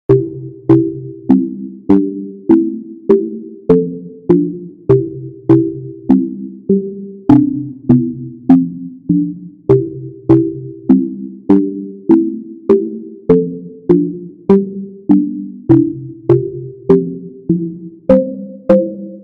環境ゲームとかにあいそうなイメージです。ループ対応。
BPM100